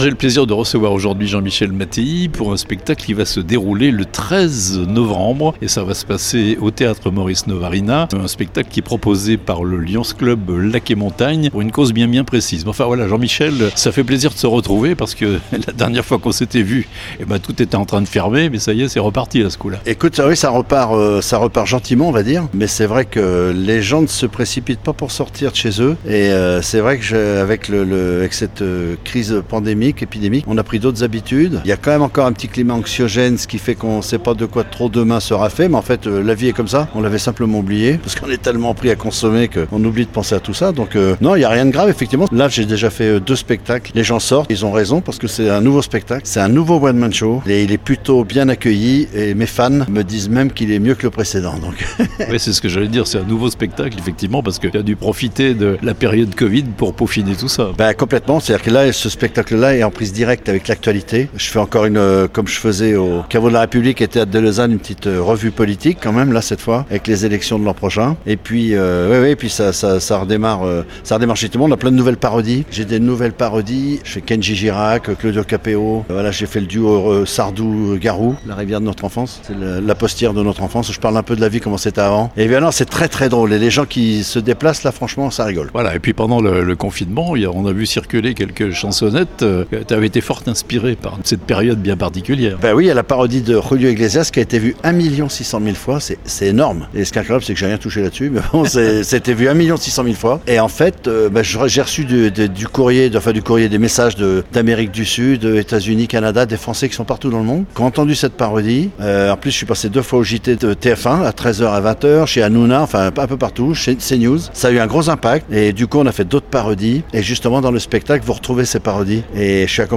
(interview)